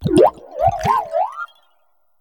Cri d'Olivado dans Pokémon HOME.